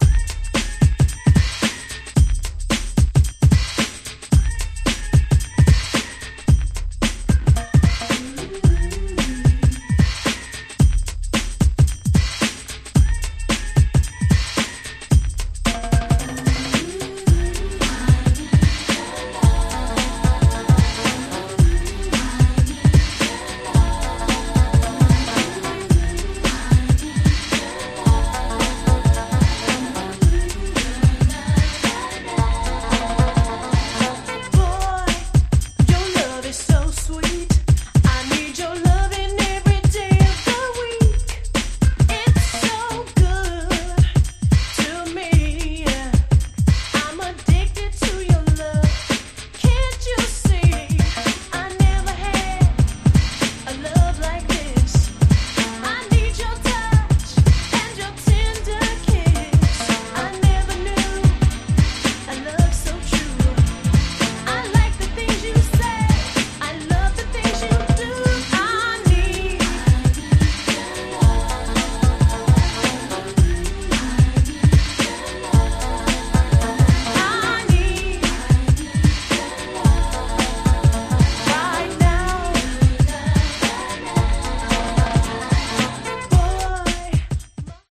New Jack Swing Classic !!
Beatもしっかりしてるし歌も良いし完璧です！！
90's NJS ハネ系 ニュージャックスウィング キャッチー系